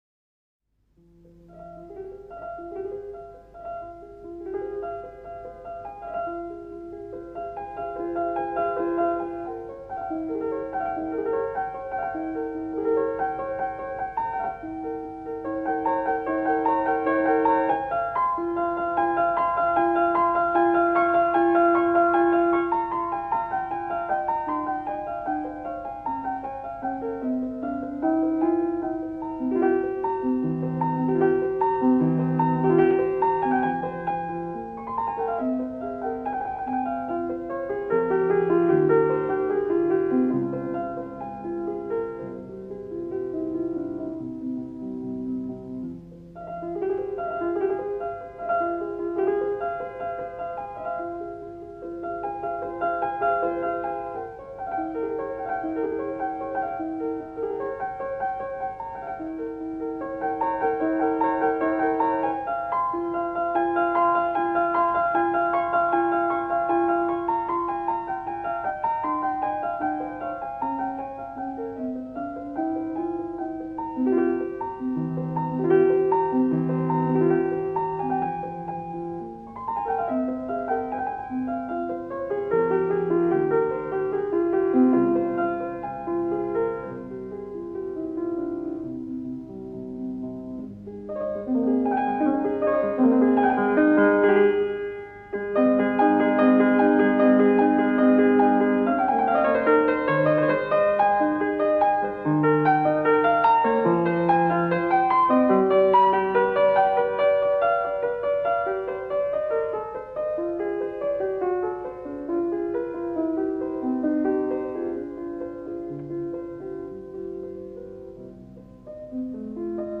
Перекликание птиц (открыта)